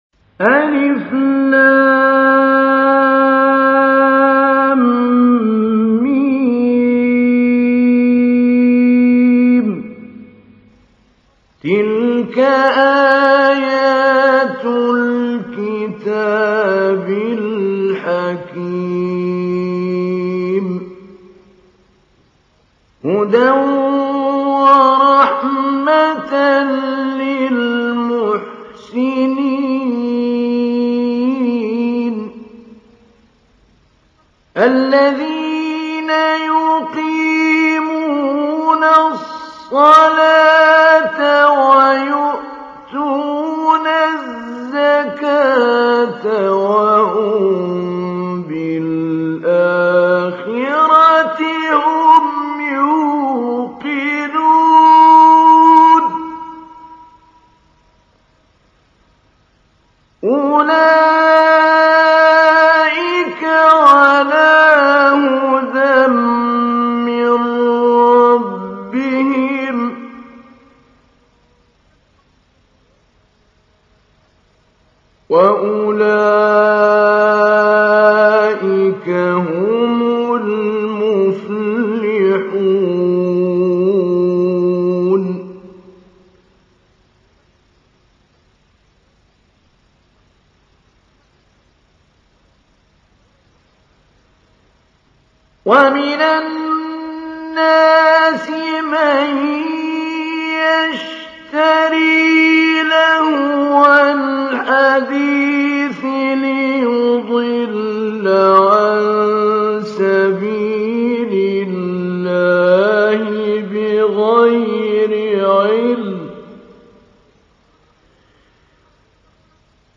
تحميل : 31. سورة لقمان / القارئ محمود علي البنا / القرآن الكريم / موقع يا حسين